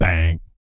BS BANG 03-R.wav